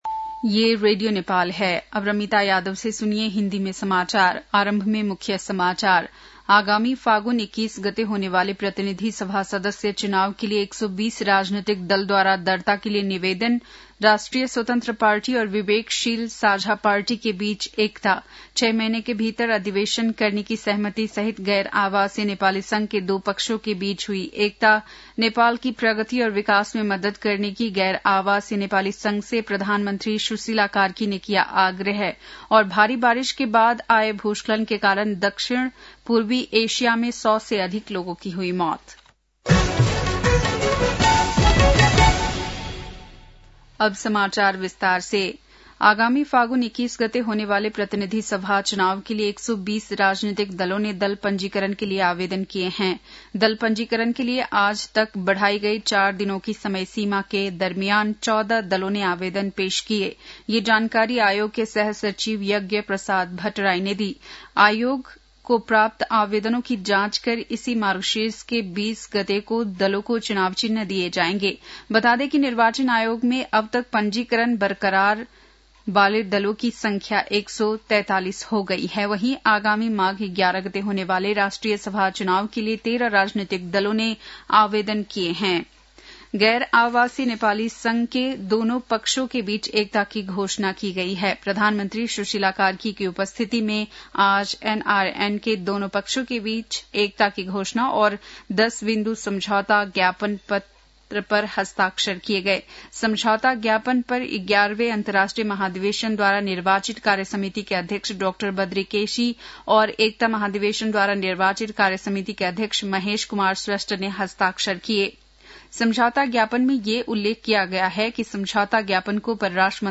बेलुकी १० बजेको हिन्दी समाचार : १४ मंसिर , २०८२
10-pm-hindi-news-.mp3